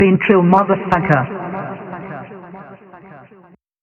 TS - CHANT (5).wav